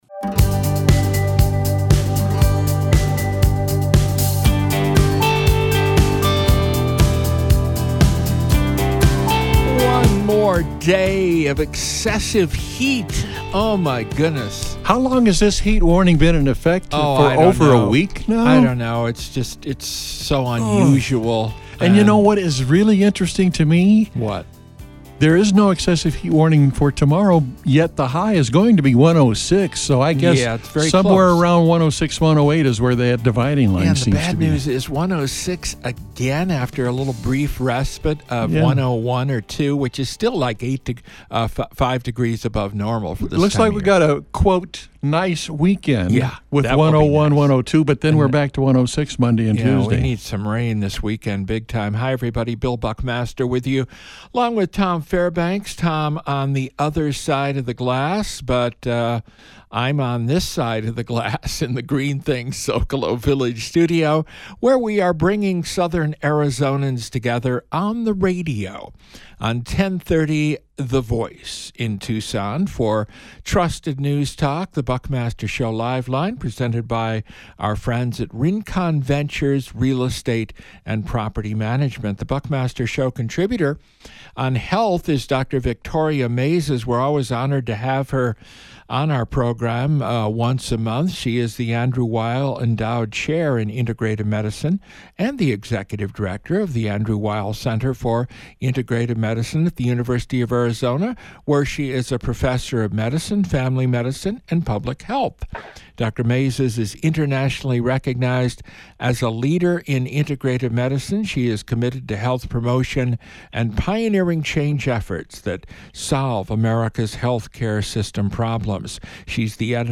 A newsmaker interview